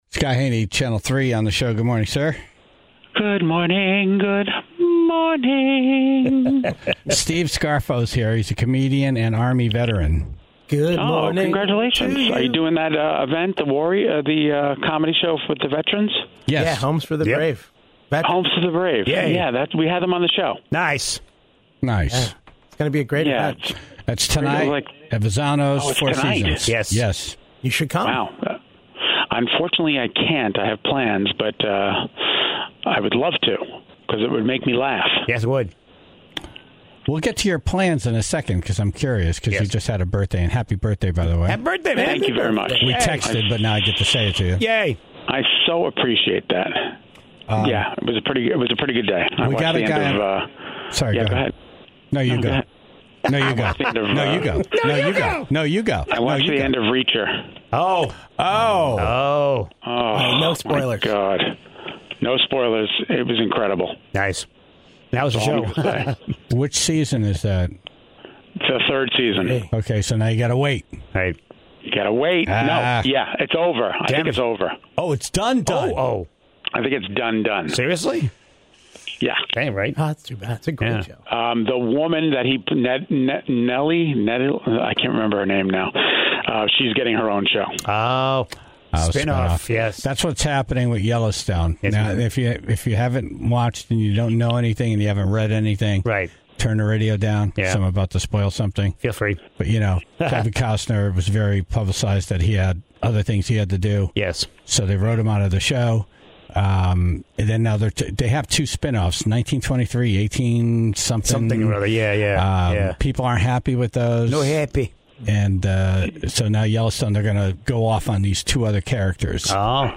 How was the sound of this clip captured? (15:37) The Tribe called in their pet stories, after hearing about a woman who spent over $13,000 to remove five pairs of underwear that her dog had eaten.